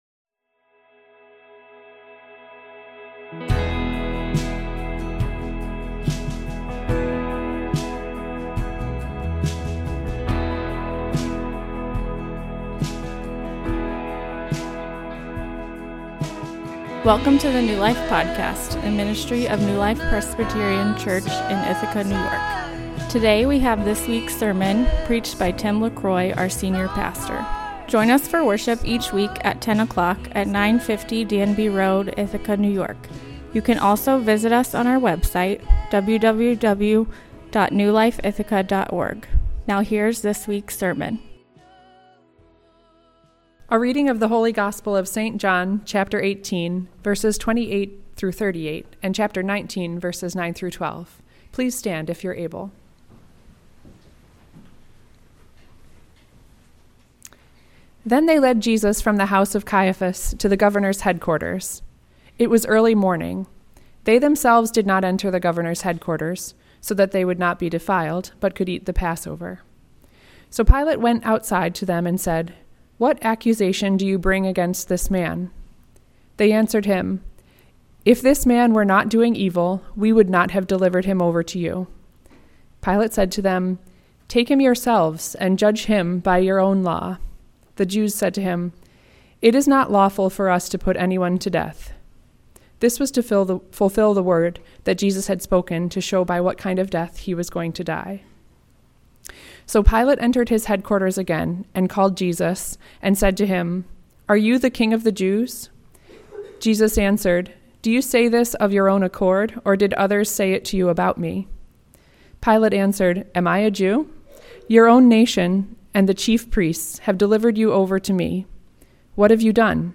In this sermon we explore Jesus’ interaction with Pontius Pilate, Paul’s teaching from Romans 13, and Peter’s teaching from 1 Peter 2.